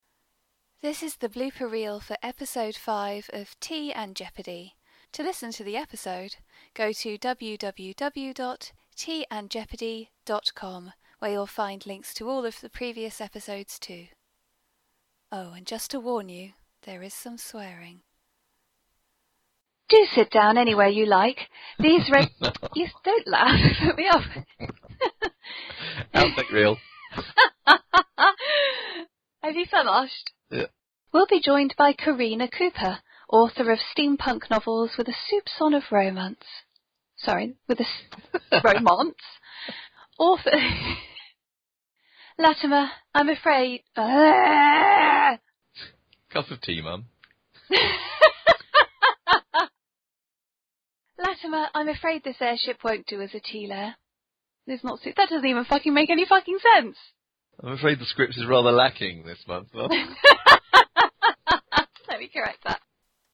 A little blooper reel for episode 5 of Tea and Jeopardy (first time I've done this).